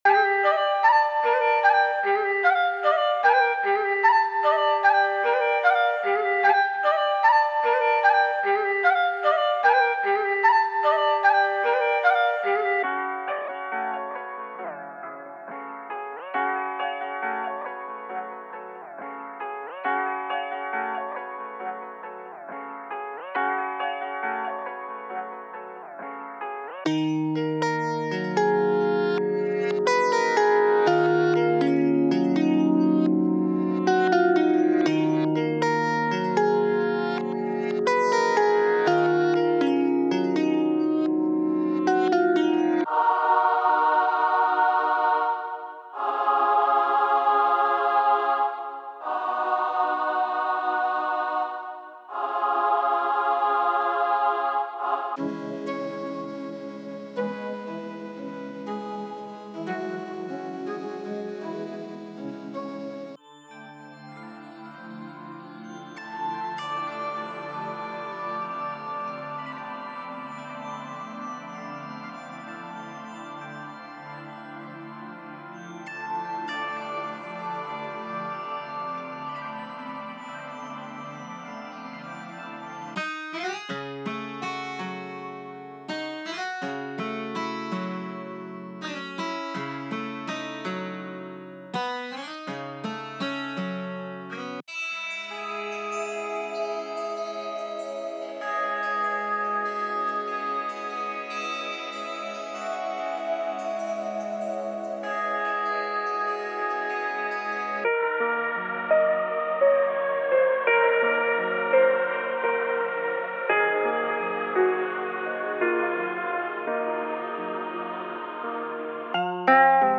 3. Trap